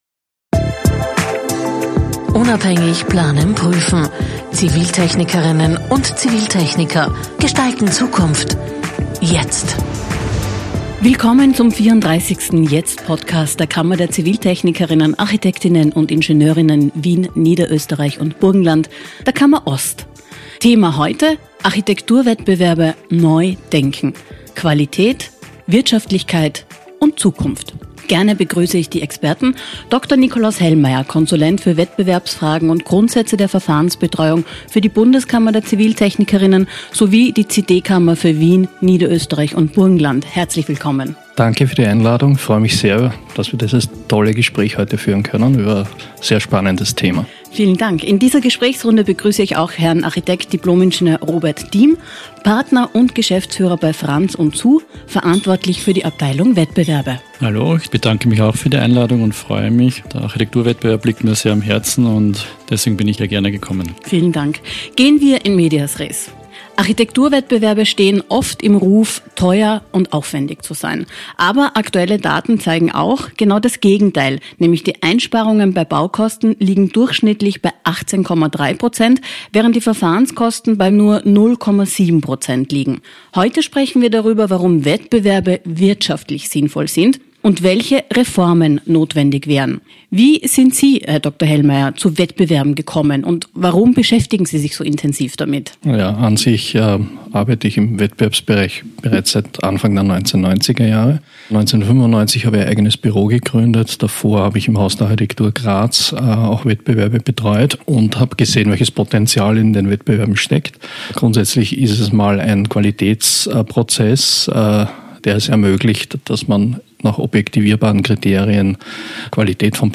Ein Gespräch über Zahlen, Qualität – und die Zukunft der Architekturwettbewerbe.